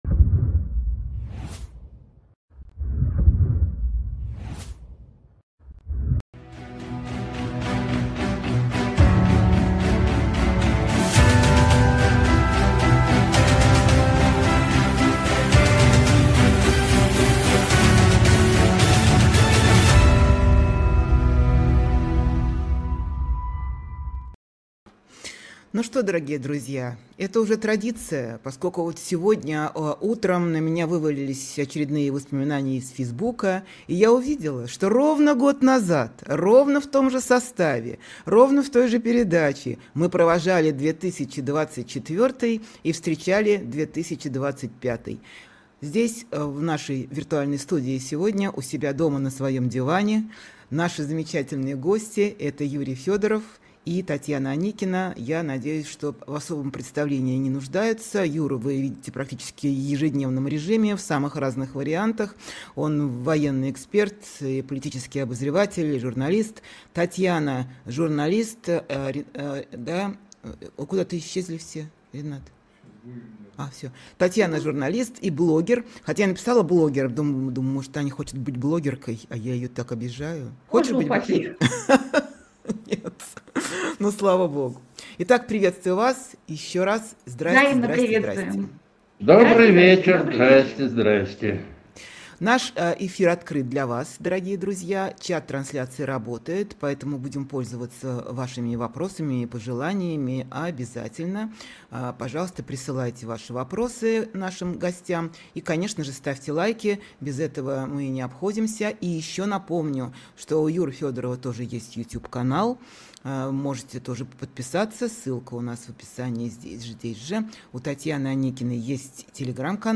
Эфир ведёт Ксения Ларина
военно-политический эксперт
независимый журналист